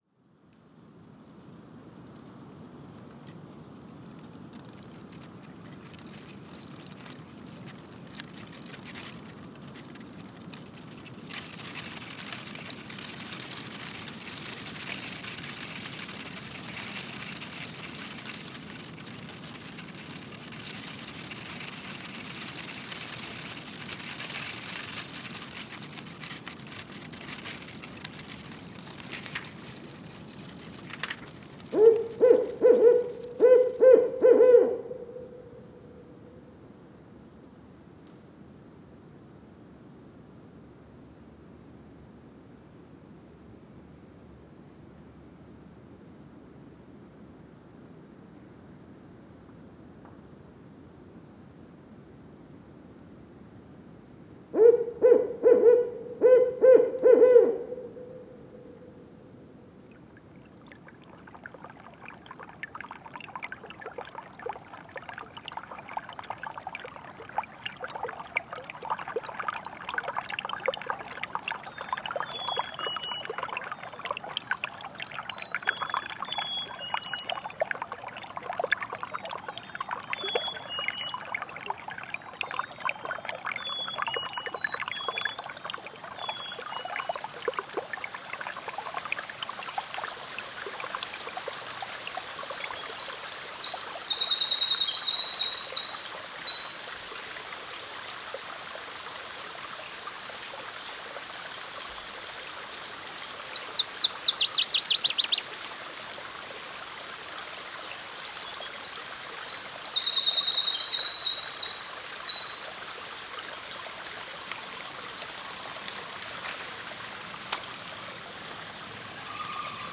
La forêt bruisse, craque, souffle, frétille, coule, respire, chante. La trame sonore engendrée par les organismes et les éléments est une création impermanente et spontanée qui oscille avec les cycles naturels du jour, des saisons et des perturbations.